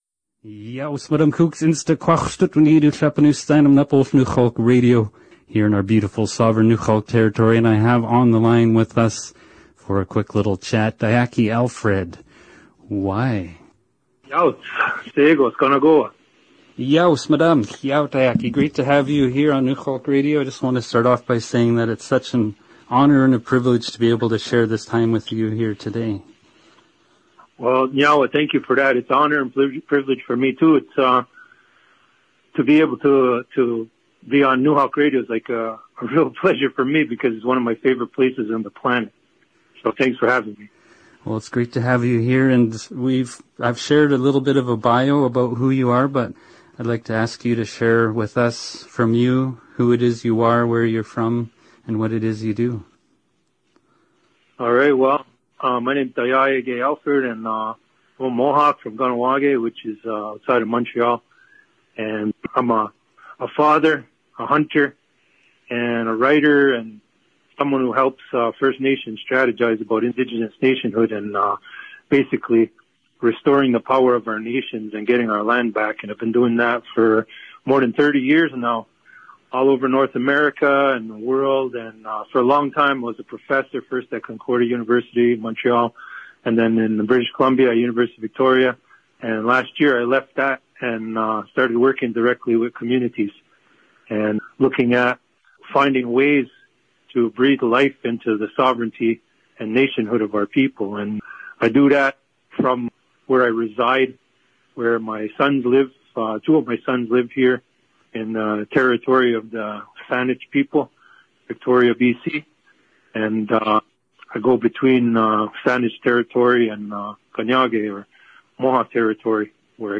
extended interview